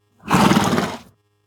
horse_snort1.ogg